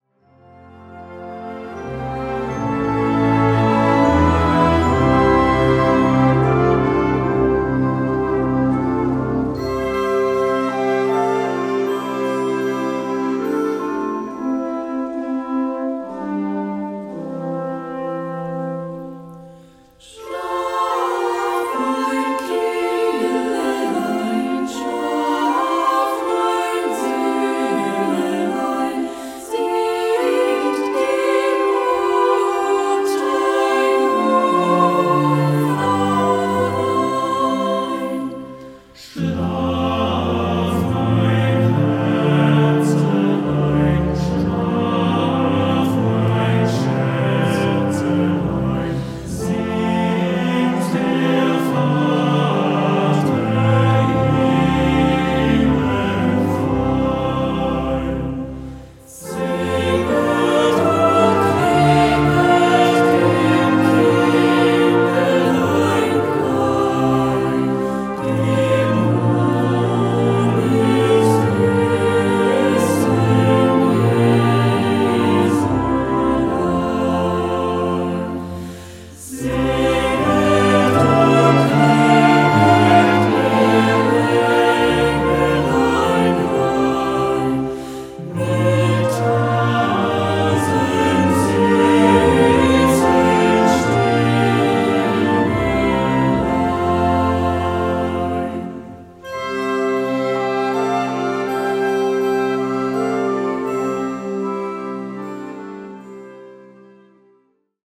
Gattung: Kirchenmusik mit Chor ad lib.
Besetzung: Blasorchester